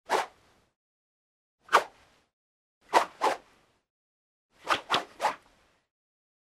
Звуки фехтования
Шум шпаги, разрезающей воздух взмах